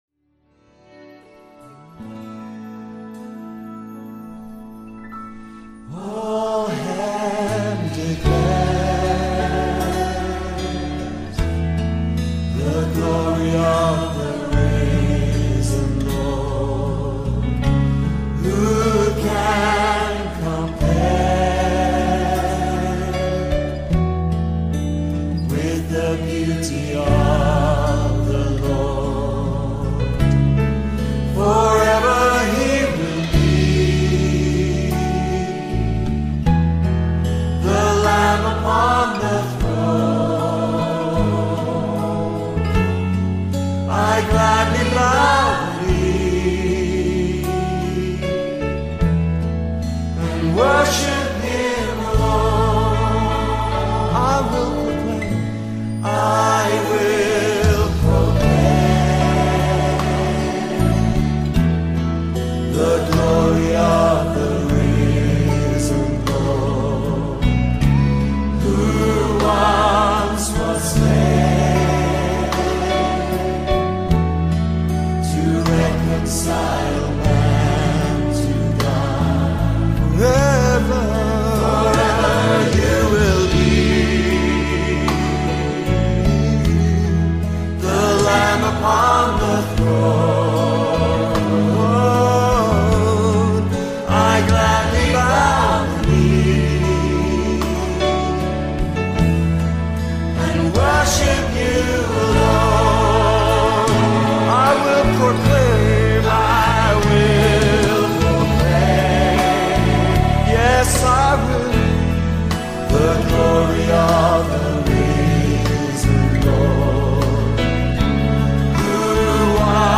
Welcome to this time of worship.